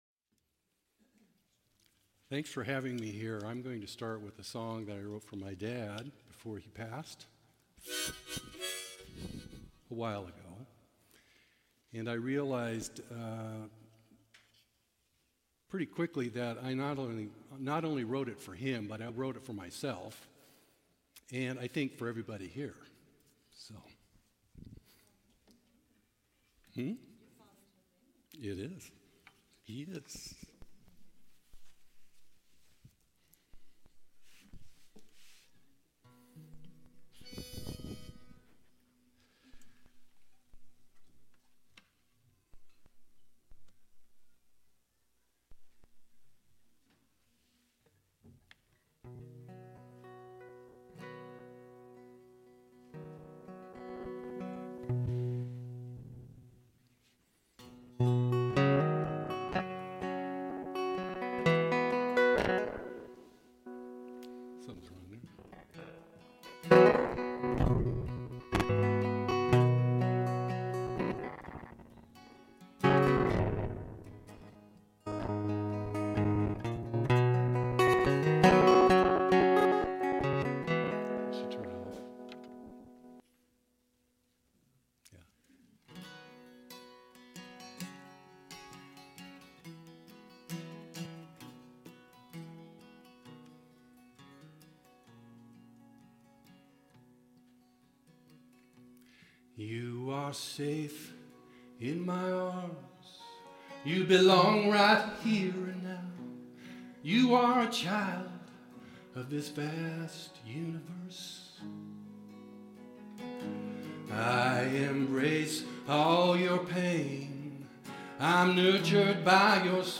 The audio recording (below the video clip) is an abbreviation of the service. It includes the Lesson and Featured Song. This service had no meditation.